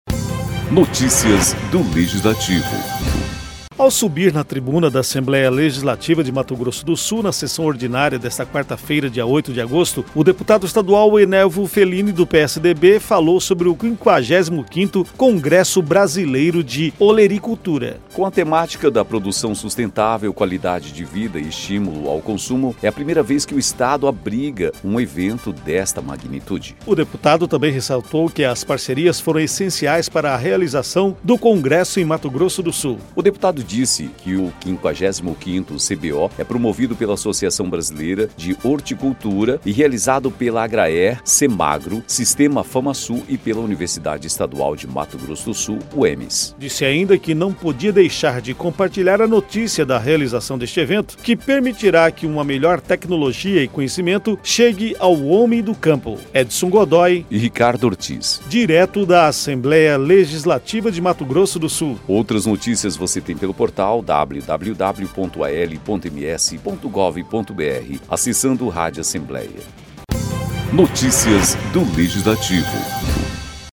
Ao subir na tribuna da Assembleia Legislativa de Mato Grosso do Sul, na sessão ordinária desta quarta-feira (8), o deputado estadual Enelvo Felini (PSDB) falou sobre o 55º Congresso Brasileiro de Olericultura (55º CBO).